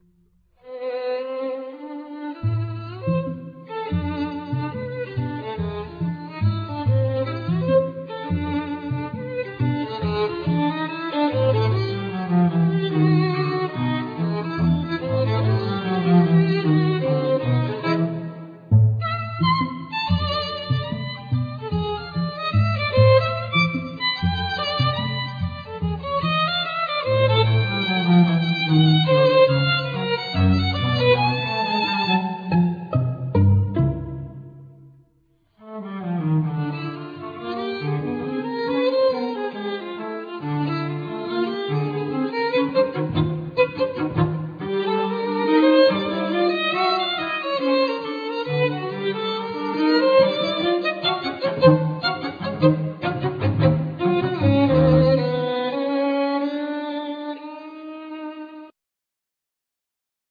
Piano
1st Violin
2nd Violin
Cello
Viola